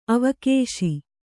♪ avakēśi